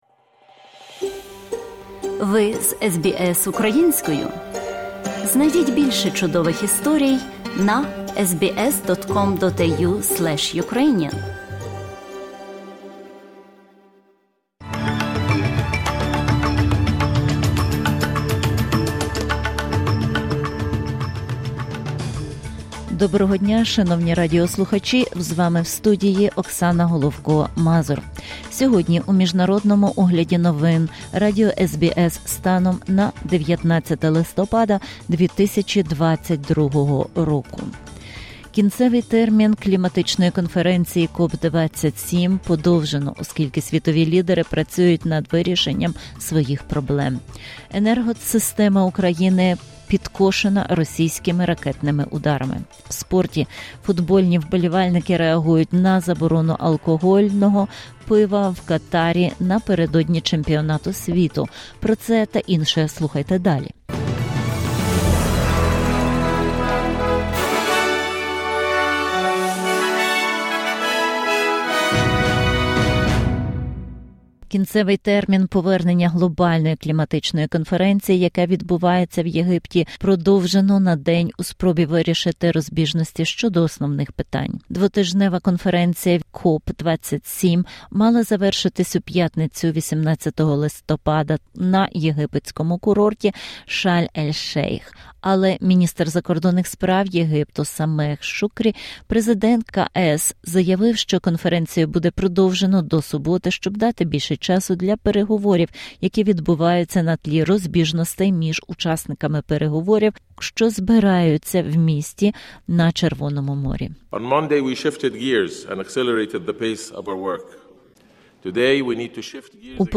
SBS news in Ukrainian - 19/11/2022